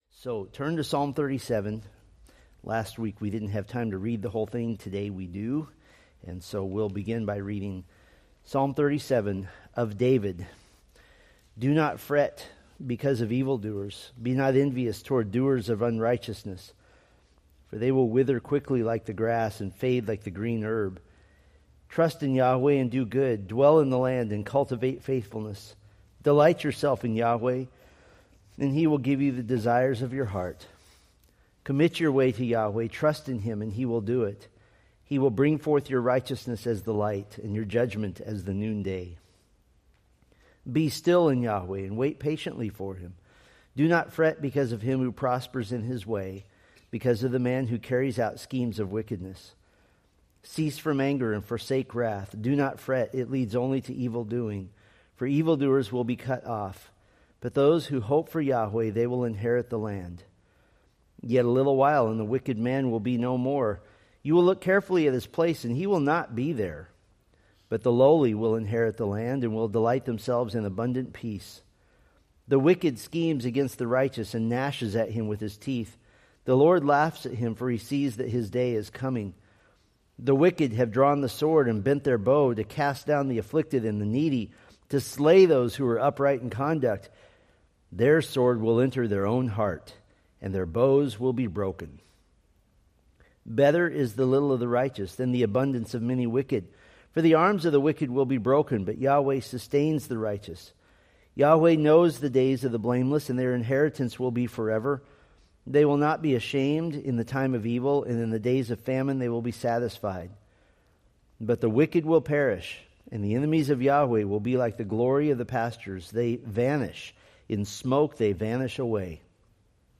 Date: May 11, 2025 Series: Psalms Grouping: Sunday School (Adult) More: Download MP3